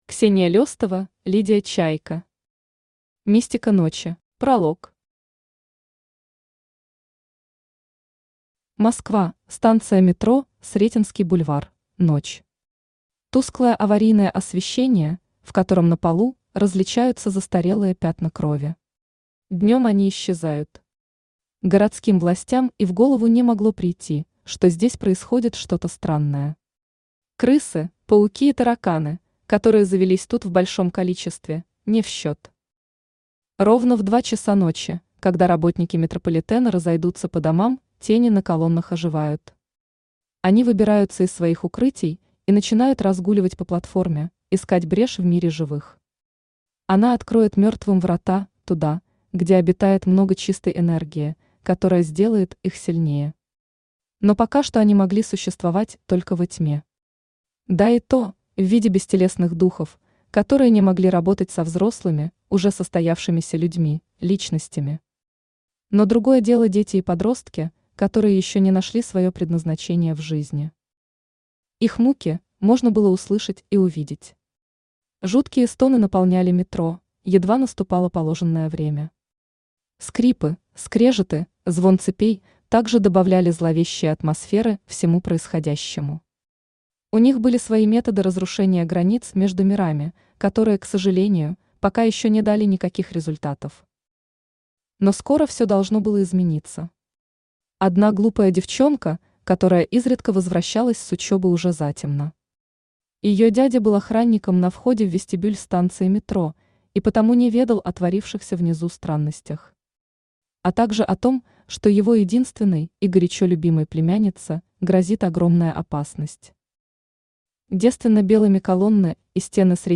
Аудиокнига Мистика ночи | Библиотека аудиокниг
Читает аудиокнигу Авточтец ЛитРес.